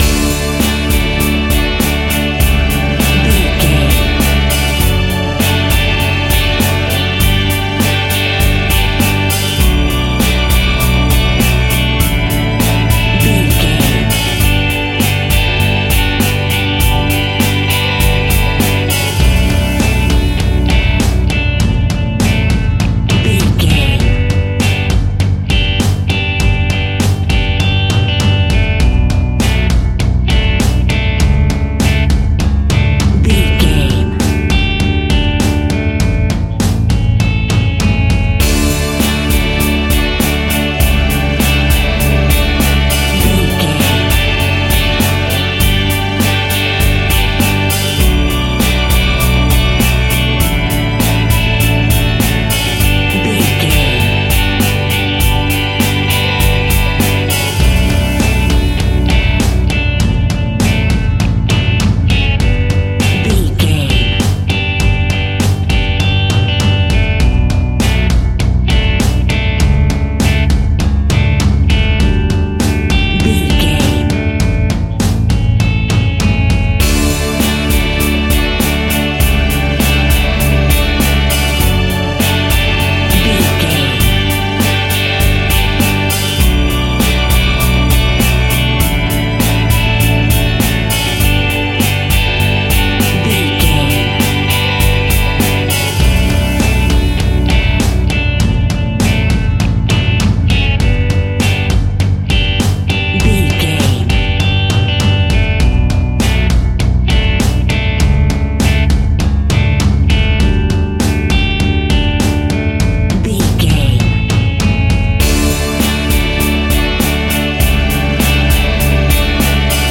Pop Rock Americana.
Uplifting
Ionian/Major
D
Bubblegum pop
cheesy
pop instrumentals
light
guitars
bass
drums
hammond organ